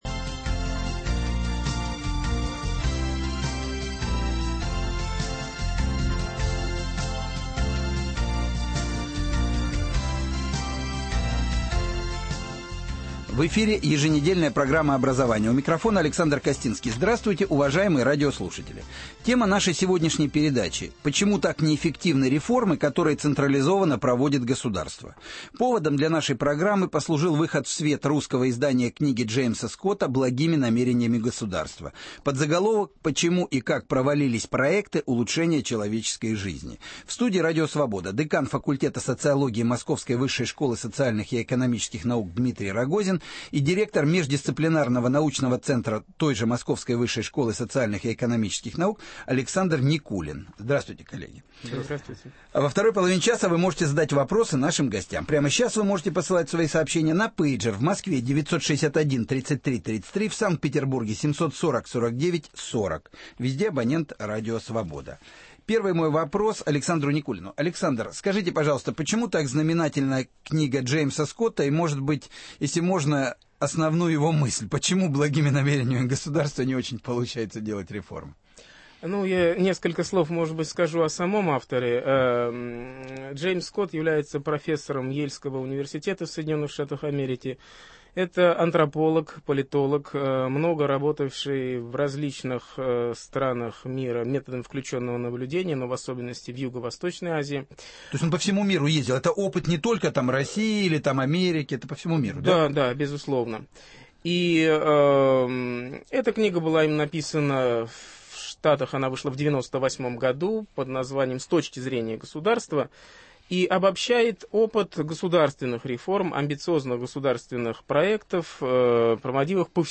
Почему так неэффективны реформы, которые централизовано проводит государство. Гость студии